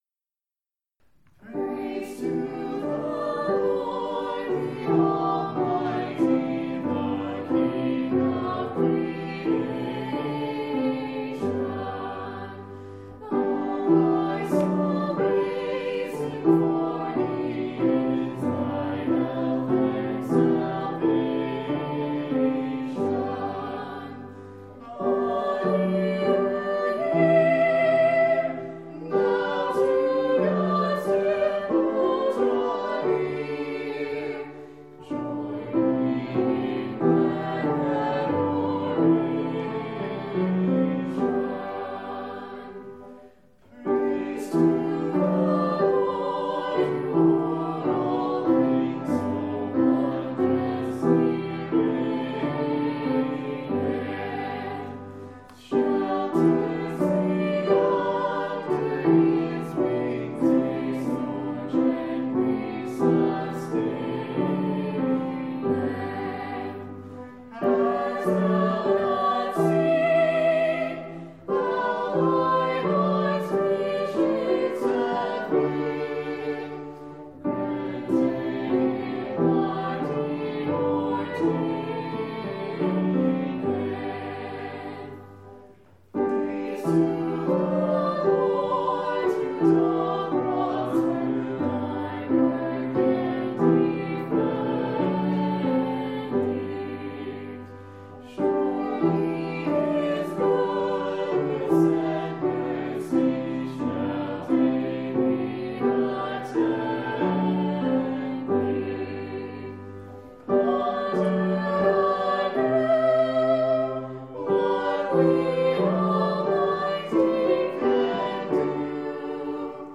Soprano
Alto
Tenor
Bass
Piano
5.17.20-Hymns.mp3